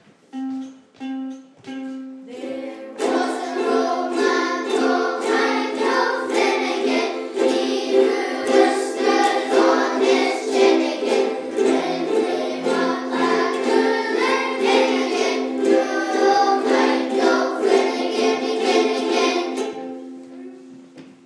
Moving between the c and f chord